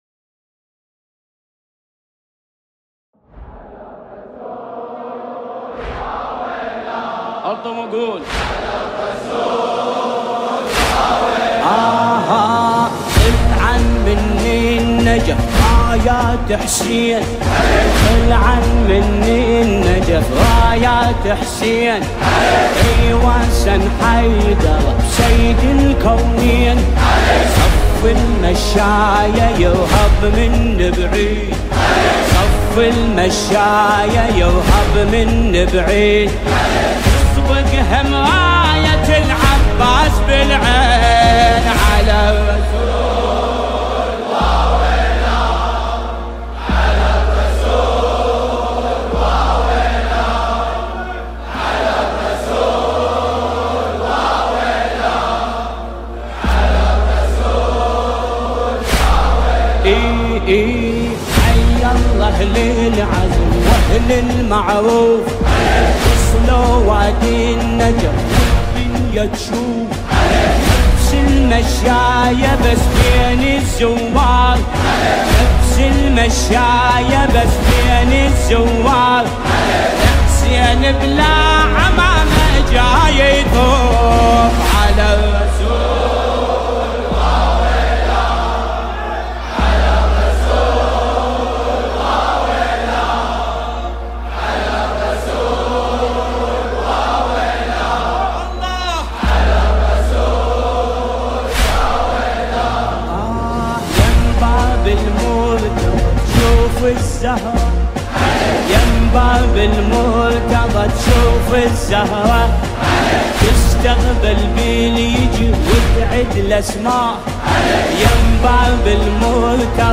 مداحی «على الرسول واويلاه»
دانلود مداحی دلنشین «على الرسول واويلاه»